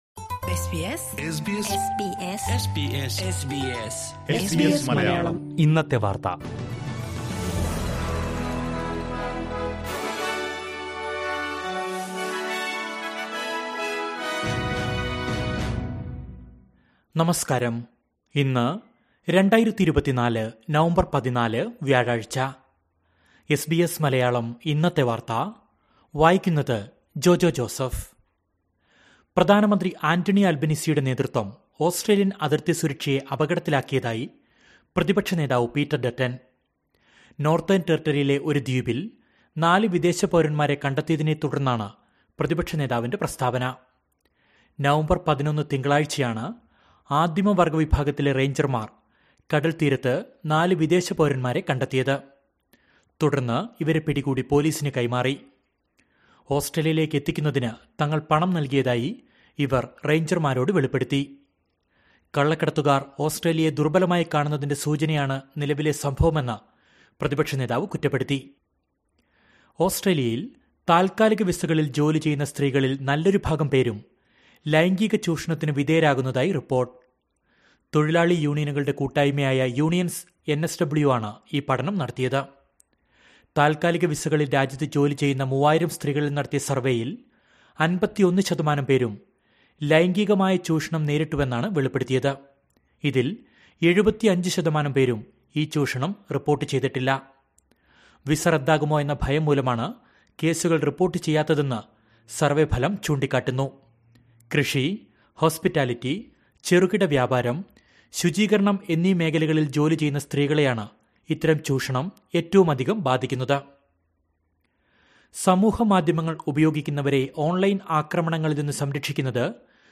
2024 നവംബർ 14ലെ ഓസ്ട്രേലിയയിലെ ഏറ്റവും പ്രധാന വാർത്തകൾ കേൾക്കാം...